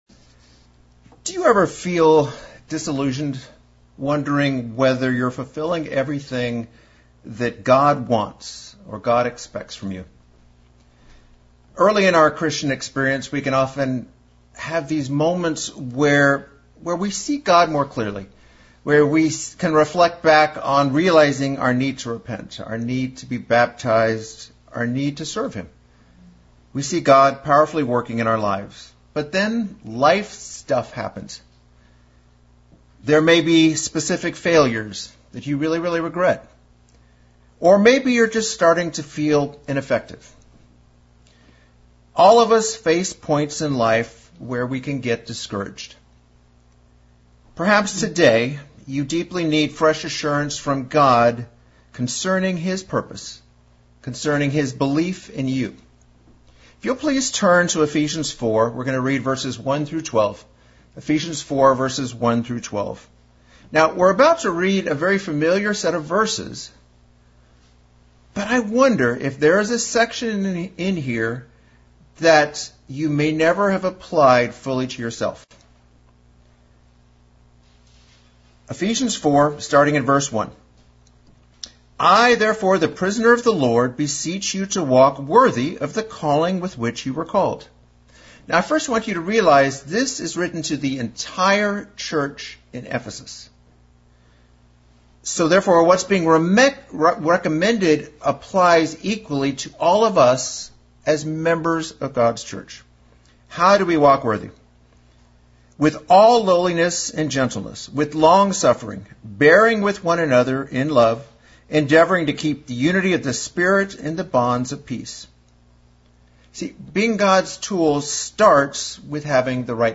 Very encouraging sermon on looking at the work of ministry in which we all play a part and why each part is important.